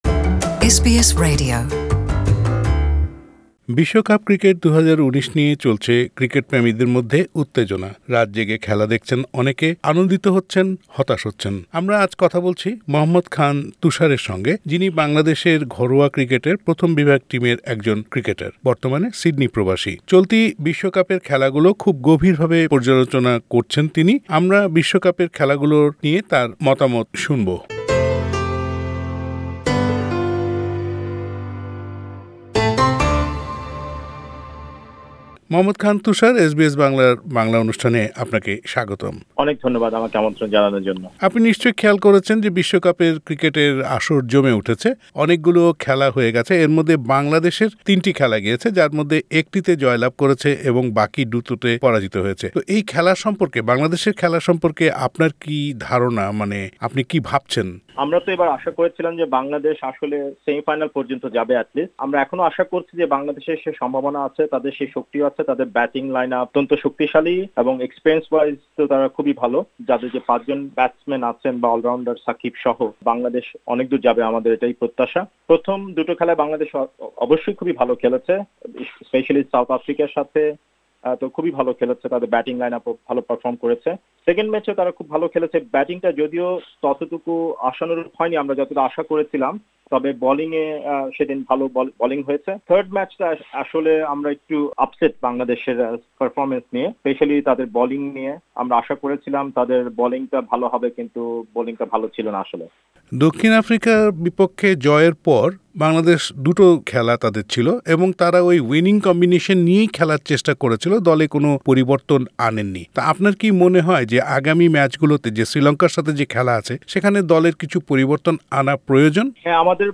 সাক্ষাৎকারটি